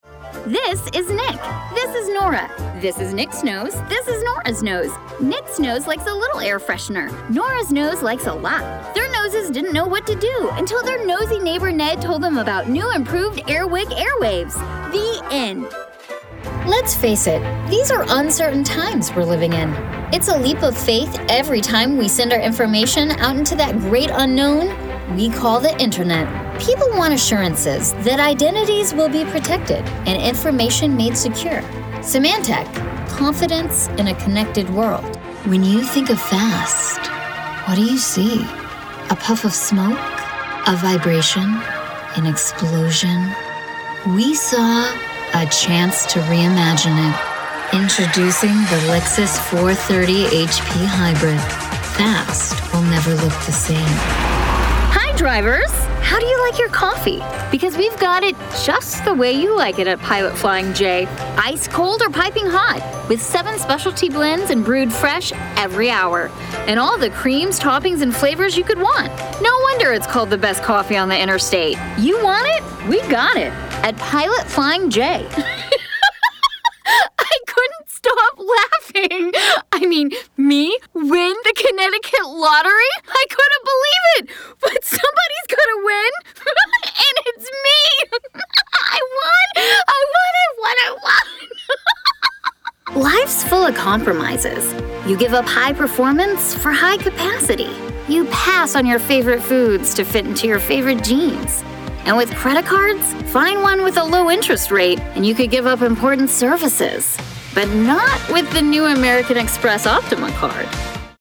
Demo
Teenager, Adult, Child, Young Adult
Has Own Studio
mid atlantic
southern us
standard us
commercial
comedic
friendly
well spoken